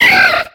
Cri de Rosélia dans Pokémon X et Y.